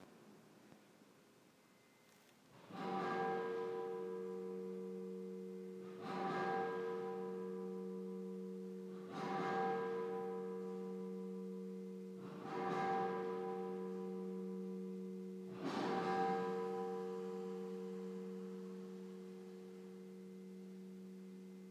St Magnus Cathedral bells 5pm, 5 November 2015
The cathedral bells have been silent since April, due to maintenance in the belfry. It's lovely to hear them back on again.